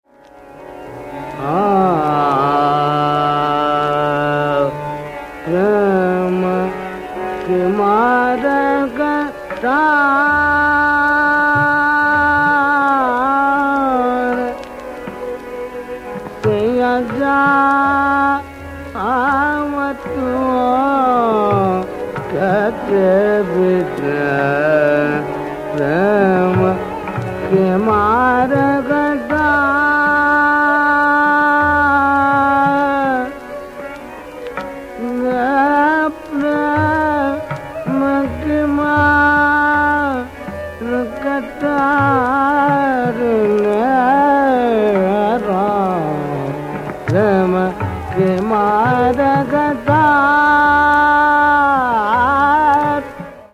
" in rāg Sohni and tāl Dīpcandī